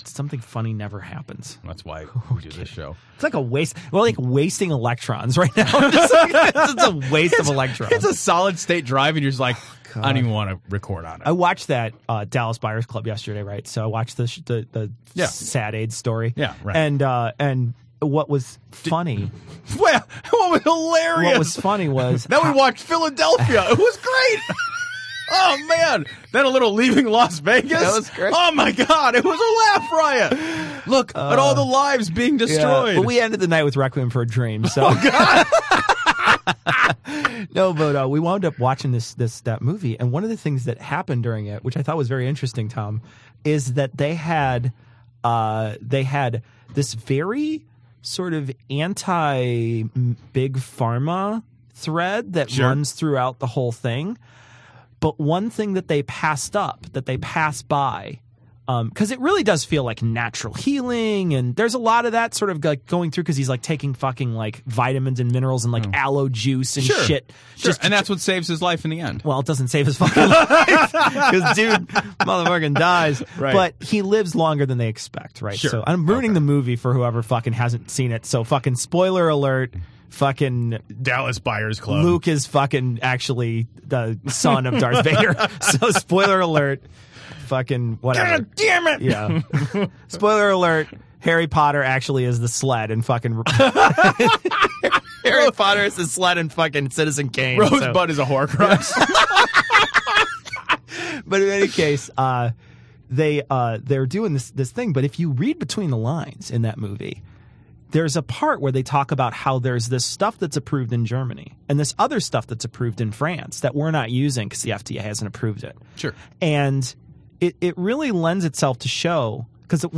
‹ previous next › Cognitive Dissonance Podcast Mic Check Chatter (Patreon) Published: 2015-05-20 16:29:07 Imported: 2024-12 ⚑ Flag Downloads Download extras.mp3 Content This is from our recording session on the 19th. It was some pre-show chatter that was recorded, we talk about Dallas Buyers Club and Juggalos.